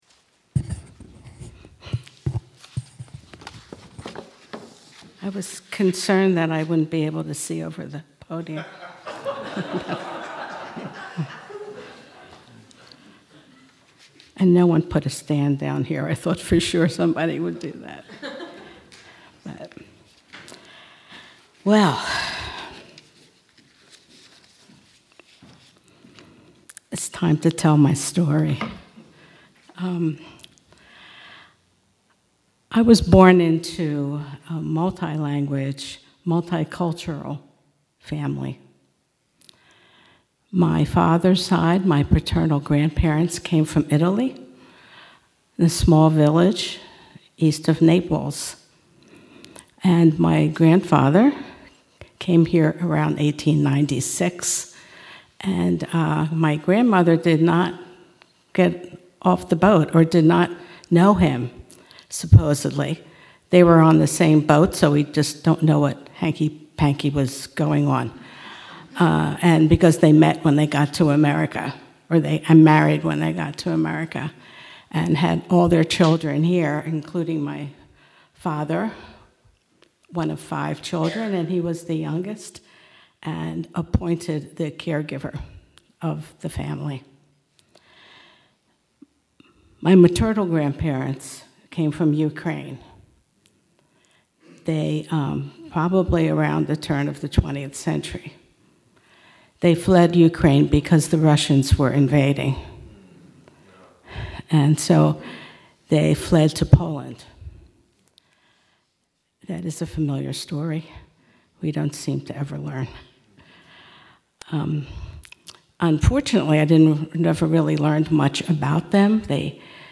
Membership-Sunday-Faith-Stories.mp3